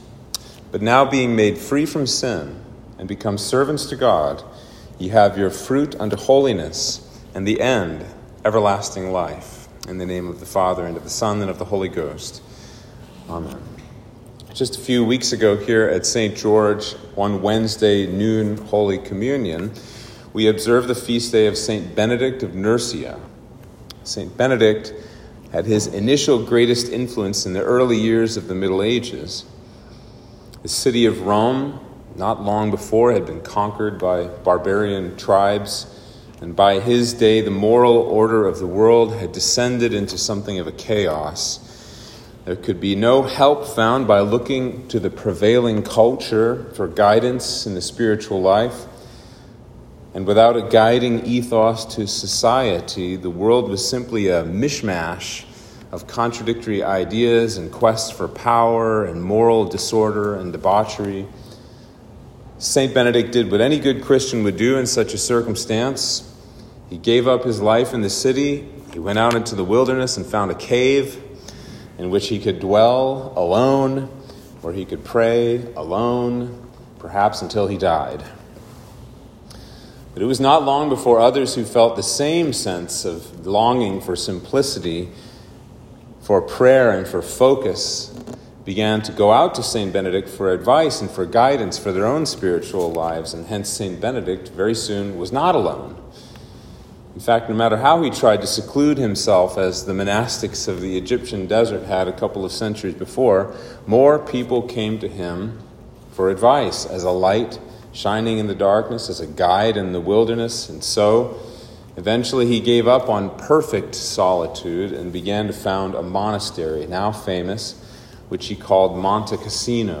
Sermon for Trinity 7